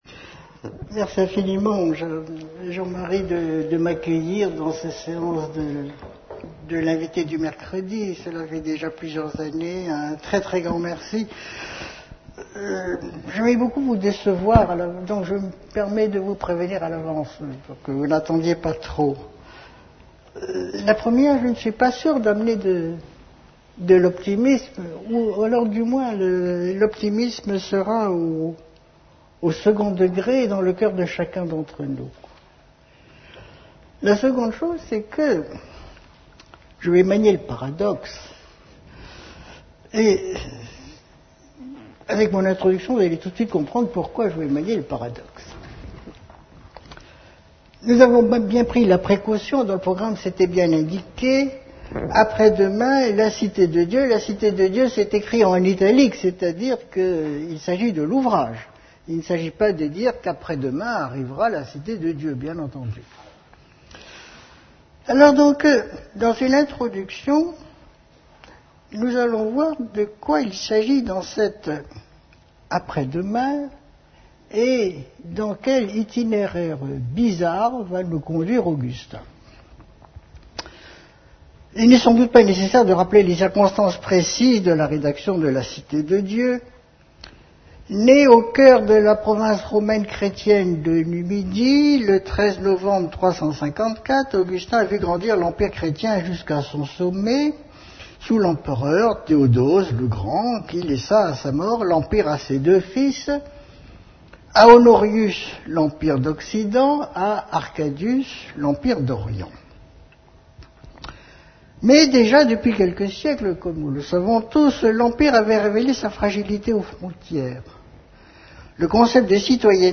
La conférence a été donnée à l'Université Victor Segalen Bordeaux 2 dans le cadre du